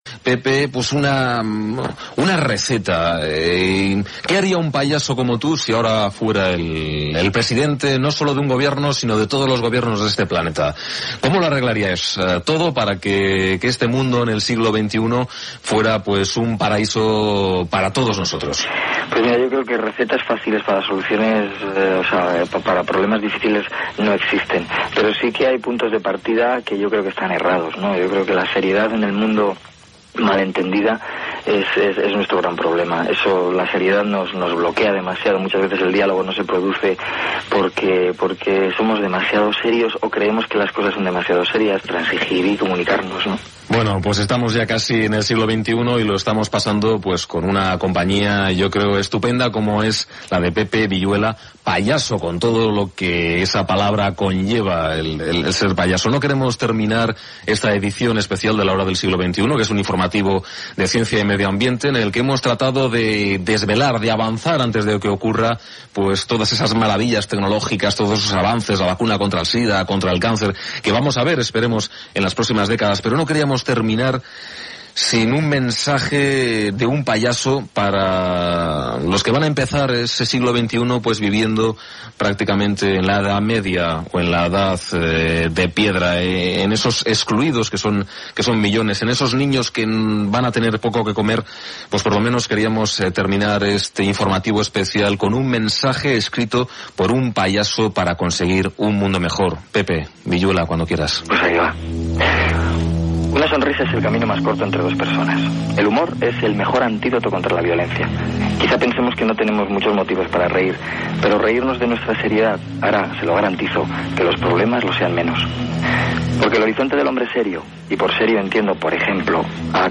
Espai especial fet a la última hora de l'any 2000. Intervenció de l'actor Pepe Viyuela i missatge seu sobre les coses importants a la vida, Comiat.
Entreteniment